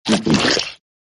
LizardLick.ogg